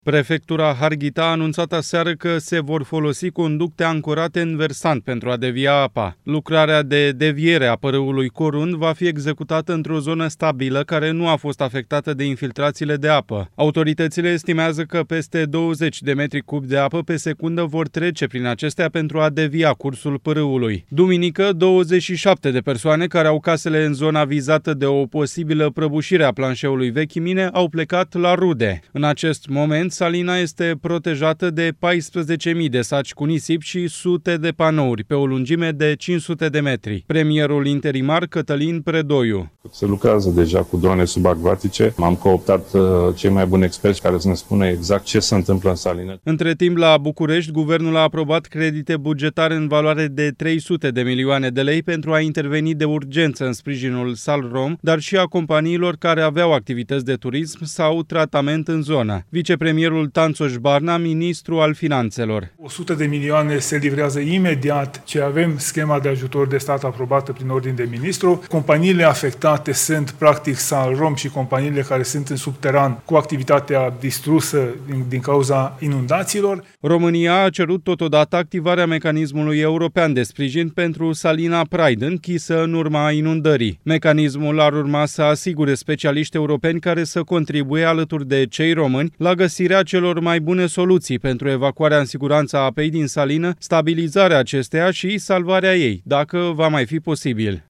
Premierul interimar, Cătălin Predoiu:
Vicepremierul Tanczos Barna, ministru al Finanţelor: